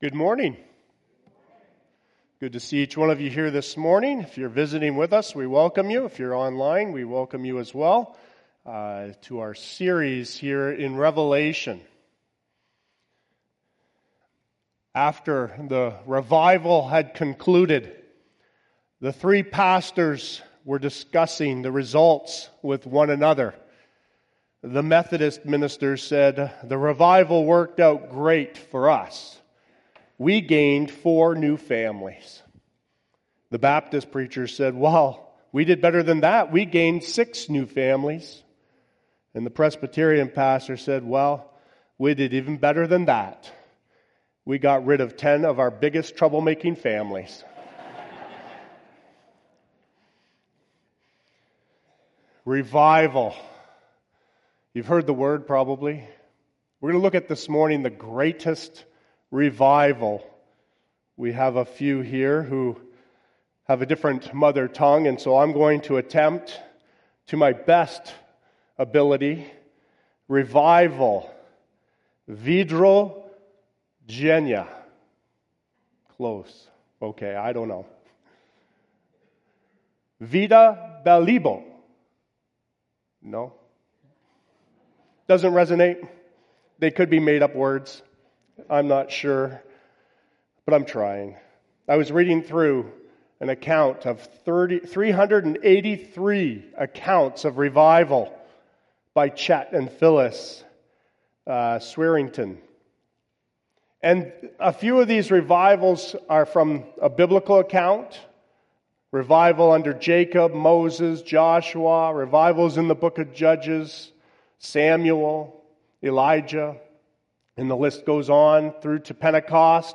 Sermons | Forest Baptist Church